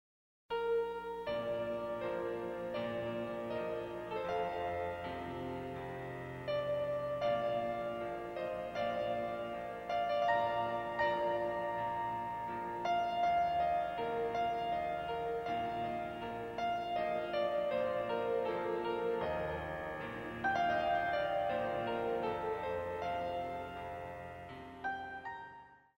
46 Piano Selections.